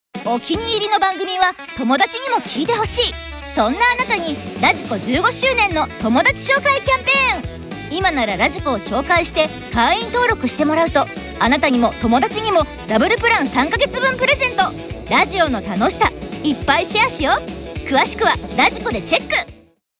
radiko15周年記念「お友達ご紹介キャンペーン」CM 20秒/40秒